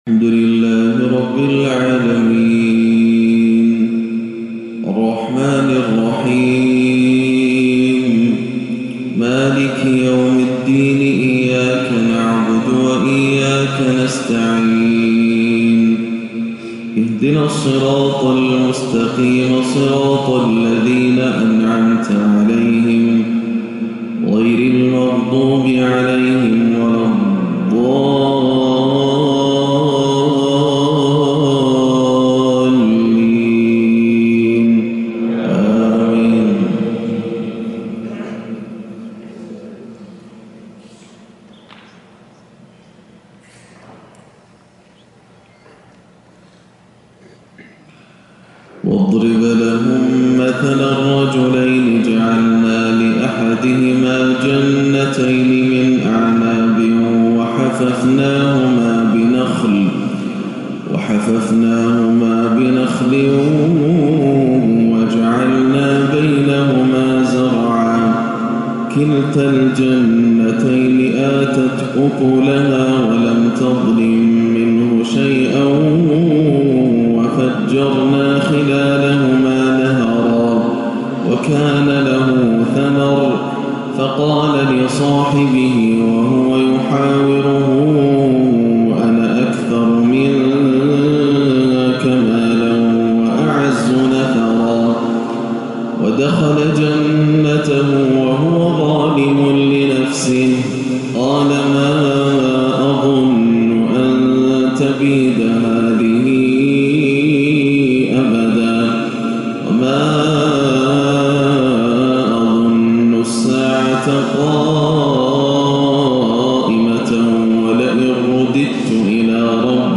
فجر الأحد 1-3-1439هـ من سورة الكهف 32-50 > عام 1439 > الفروض - تلاوات ياسر الدوسري